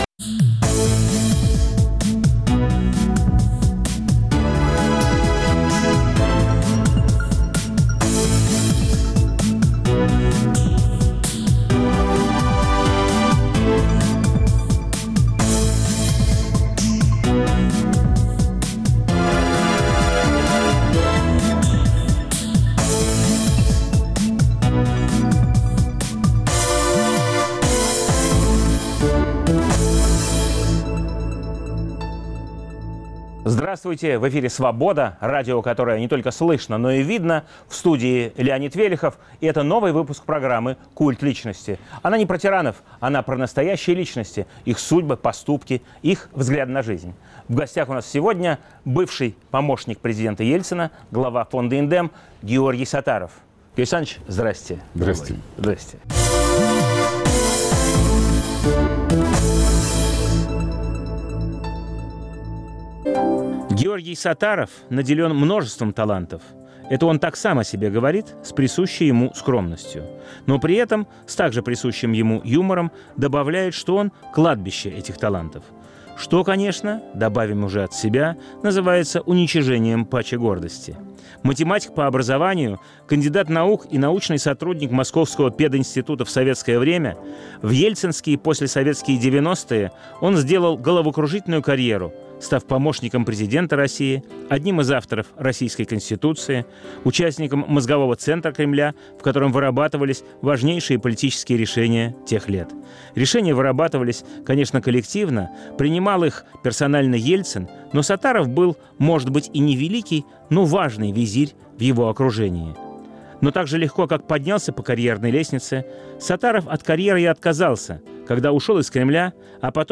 Новый выпуск программы о настоящих личностях, их судьбах, поступках и взглядах на жизнь. В студии бывший помощник президента Ельцина, глава фонда ИНДЕМ Георгий Сатаров.